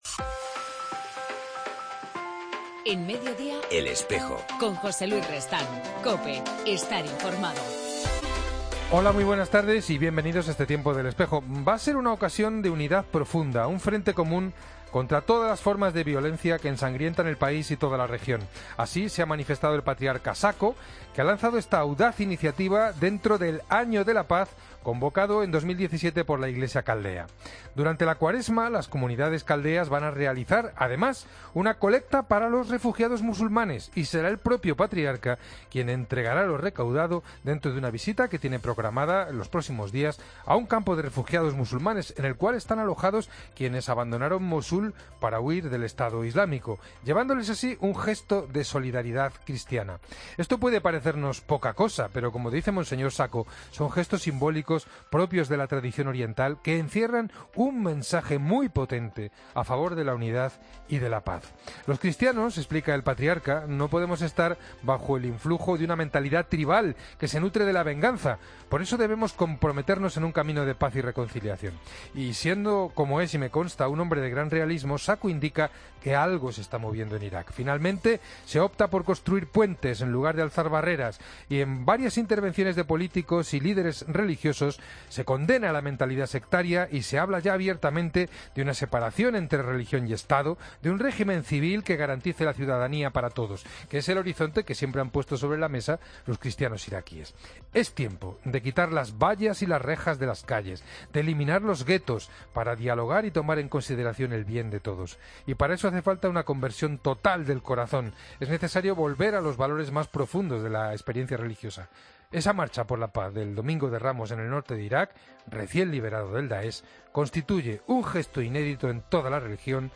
En El Espejo del 27 de febrero hablamos con Mons. Francisco Pérez sobre las Javieradas y el Año Santo Sanferminero